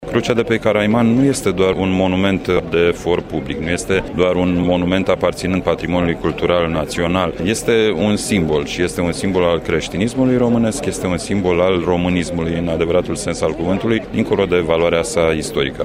La Crucea Eroilor Neamului de pe vârful Caraiman au avut loc ieri ceremonii militare şi religioase, marcând trecerea a 90 de ani de când acest monument a fost inaugurat. Ministrul apărării, Mihai Fifor a evocat memoria eroilor căzuţi în cele două războaie mondiale, dar şi în teatrele de operaţiuni din ultimii ani.